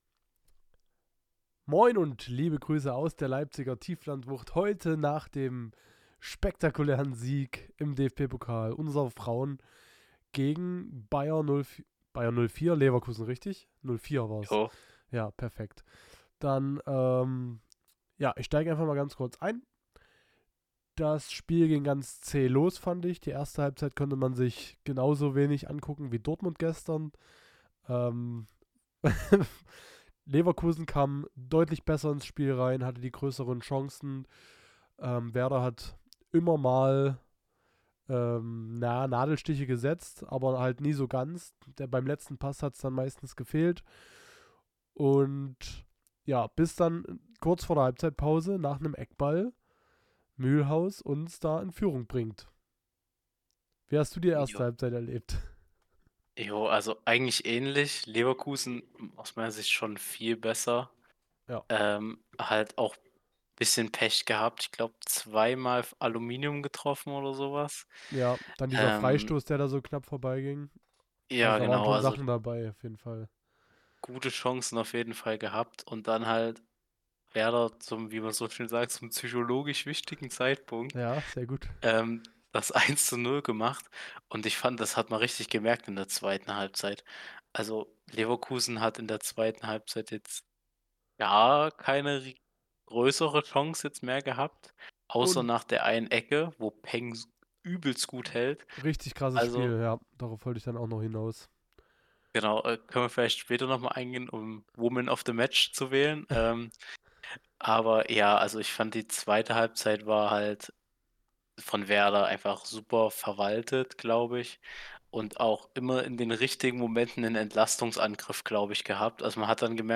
Unsere Werder-Frauen gewinnen in Leverkusen mit 1:0. Leicht erkältet und dennoch pünktlich wie immer!